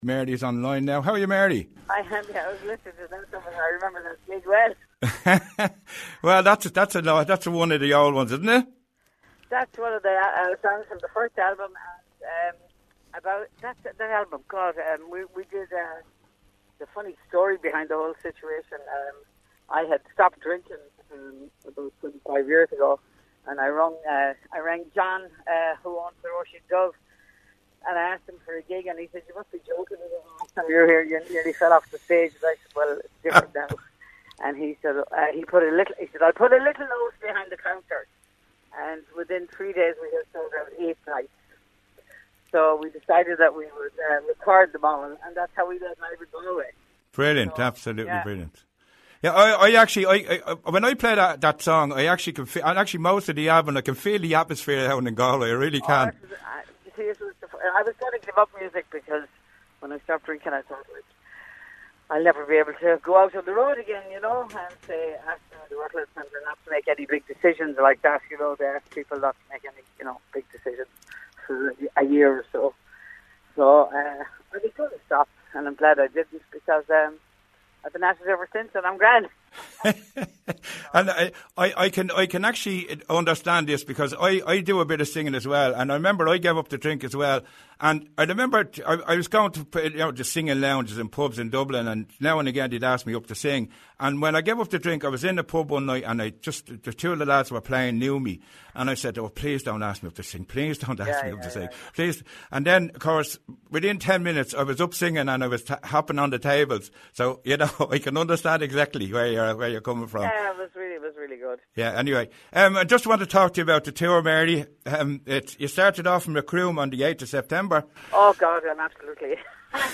Singer Mary Coughlan Interview - RosFM 94.6